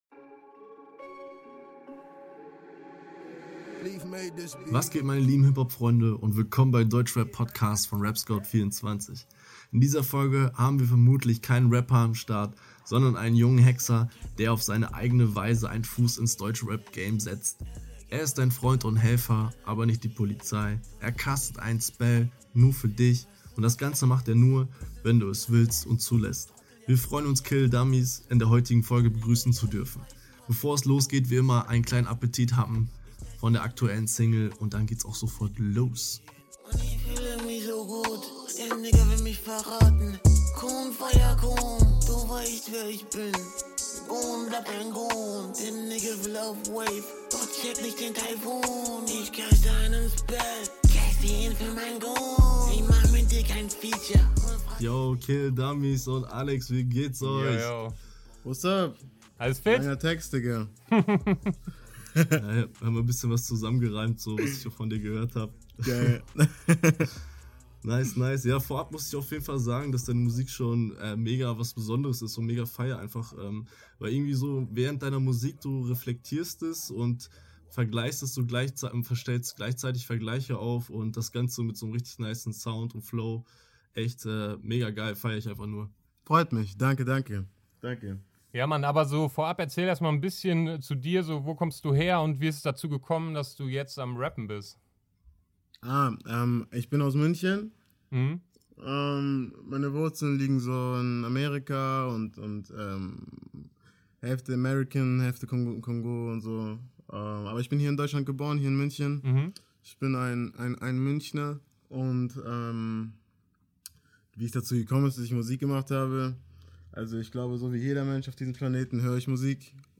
Letzte Episode #18 Interview